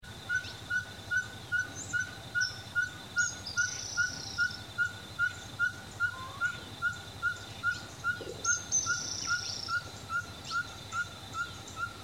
Caburé Chico (Glaucidium brasilianum)
Nombre en inglés: Ferruginous Pygmy Owl
Fase de la vida: Adulto
Localización detallada: Camino al Tobar
Condición: Silvestre
Certeza: Vocalización Grabada